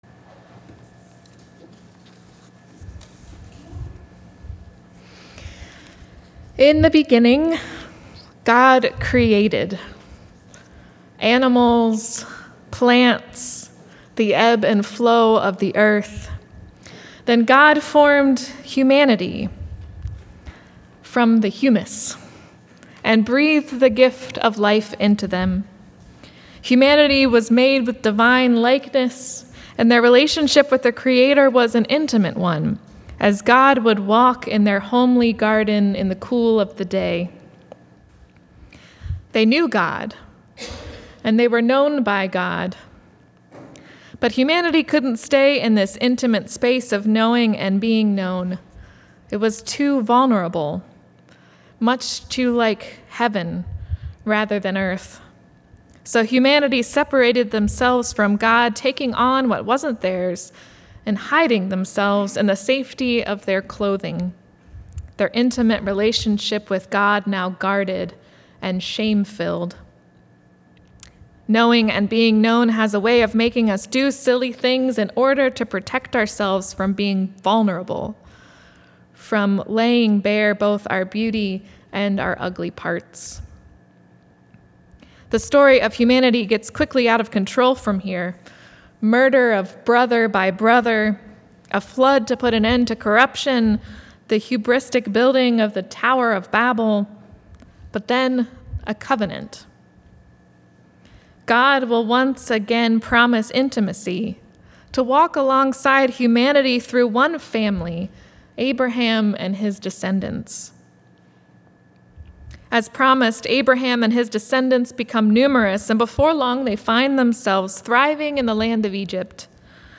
Service Type: Sunday Sermon